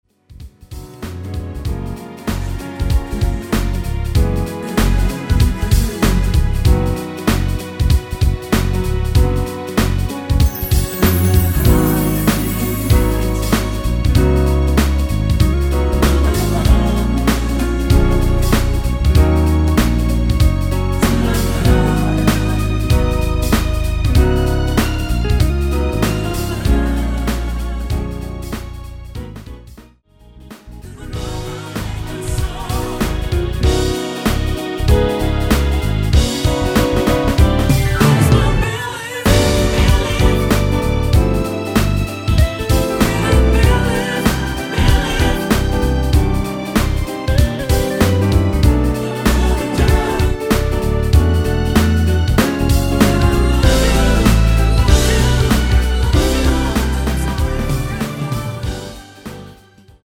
(-2) 내린코러스 포함된 MR 입니다.
F#
앞부분30초, 뒷부분30초씩 편집해서 올려 드리고 있습니다.